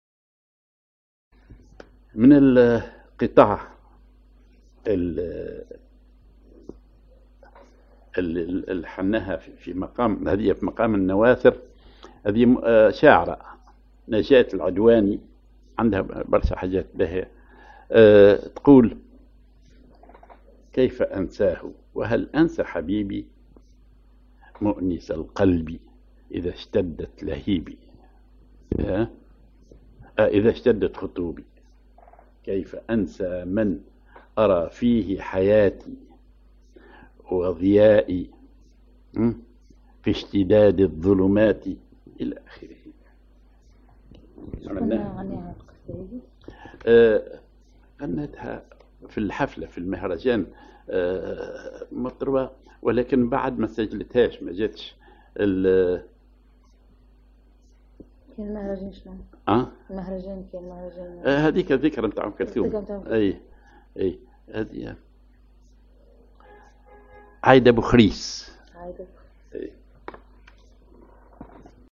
Maqam ar نواثر
Rhythm ar الوحدة
genre أغنية